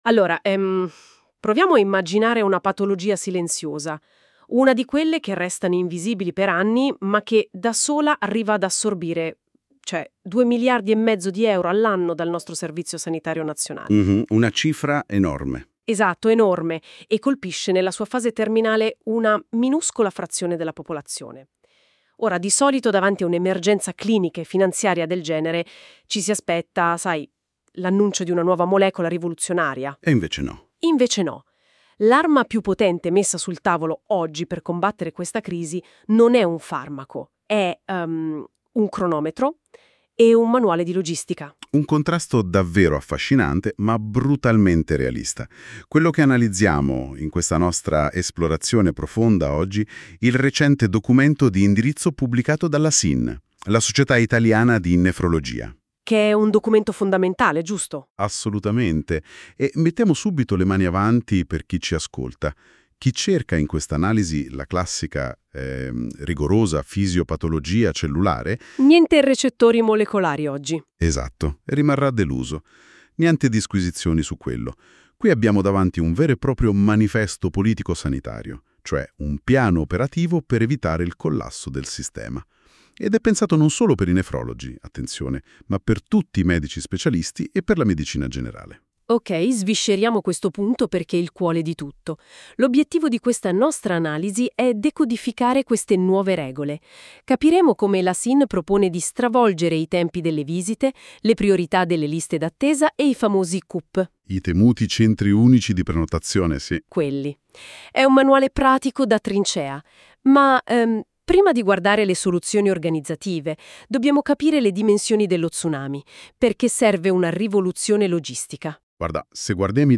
Audio sintesi Due commentatori virtuali discutono i punti chiave di questo articolo. La voce è generata tramite intelligenza artificiale, quindi la pronuncia di alcuni termini potrebbe non essere corretta.